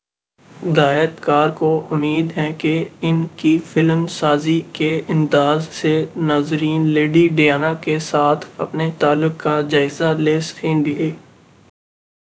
deepfake_detection_dataset_urdu / Spoofed_TTS /Speaker_15 /274.wav